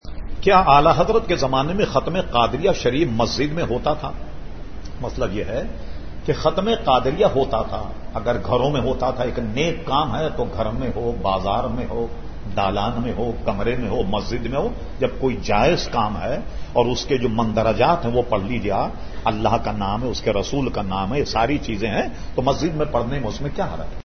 Q/A Program held on Tuesday 31 August 2010 at Jamia Masjid Ameer e Hamza Nazimabad Karachi.